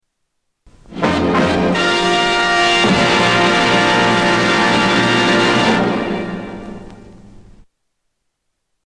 Tags: Radio Radio Stations Station I.D. Seques Show I.D